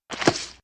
splat4.ogg